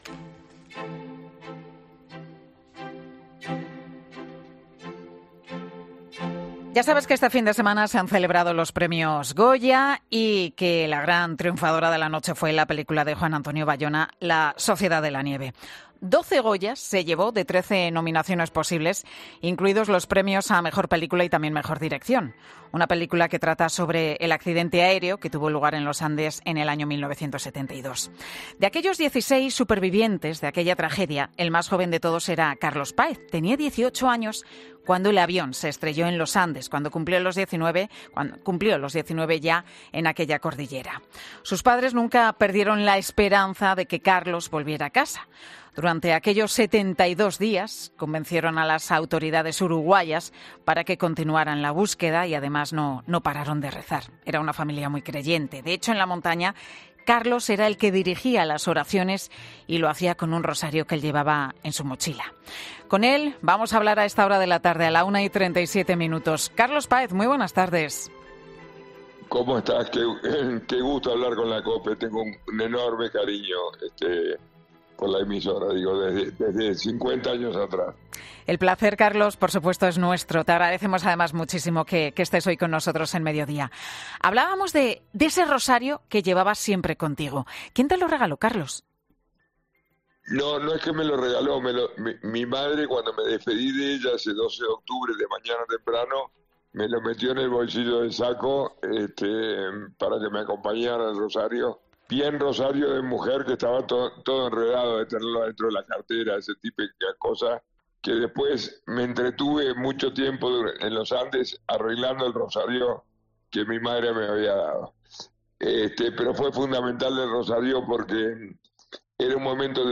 Carlos Páez, el superviviente más joven del accidente de Los Andes, le cuenta a Pilar García Muñiz su historia de superación y de fe